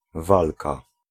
Ääntäminen
IPA: [ˈvalka]